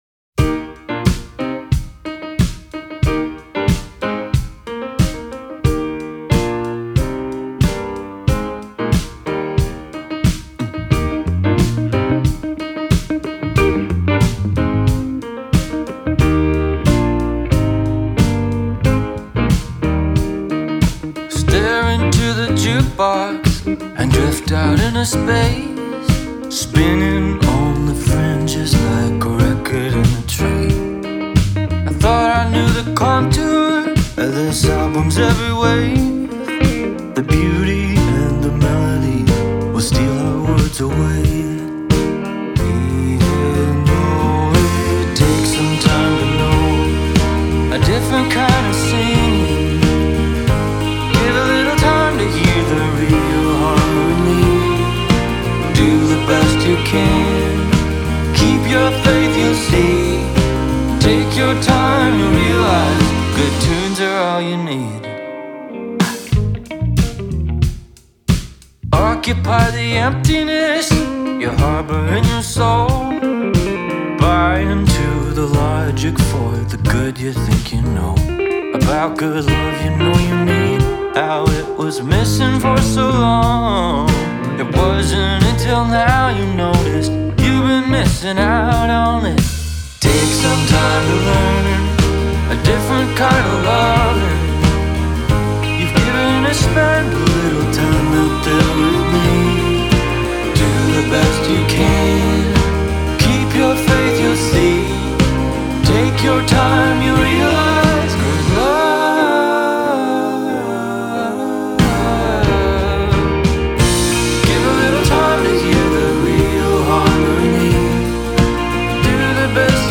a rollicking tune with a bit of slow swing.
with some nice guitar and vocal flourishes.